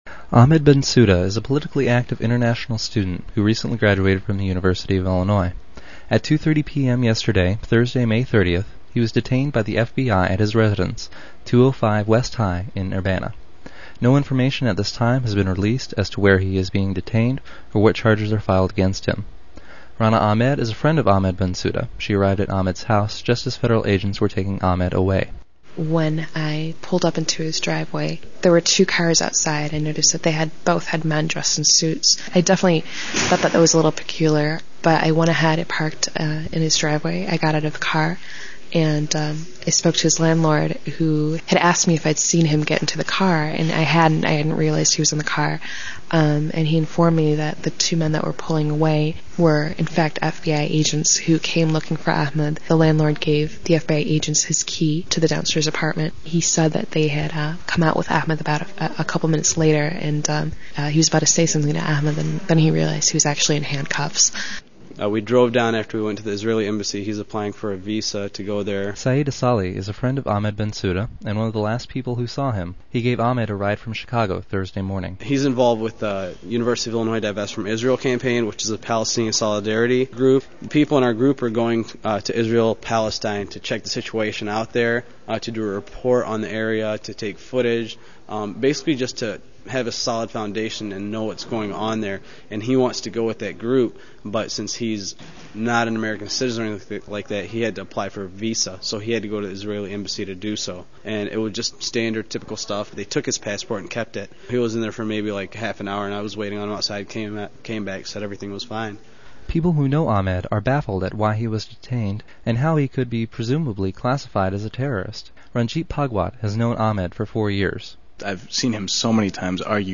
Short news segment